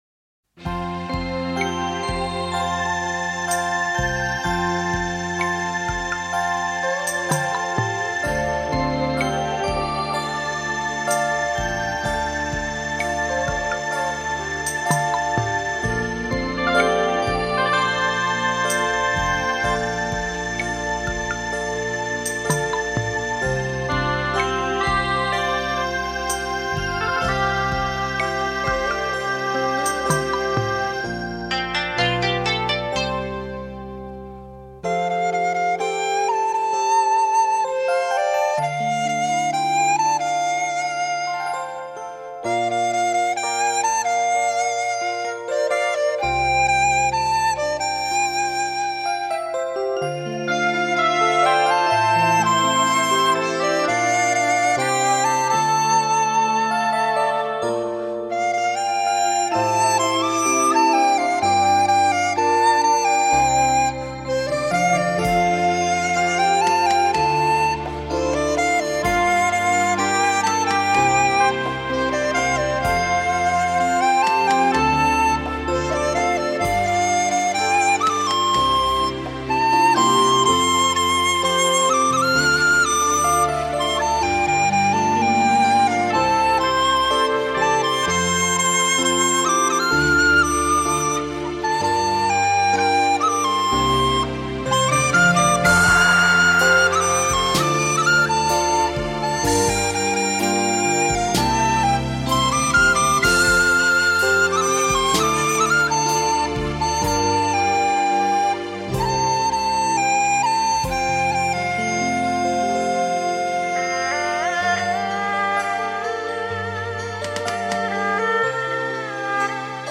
笛子演奏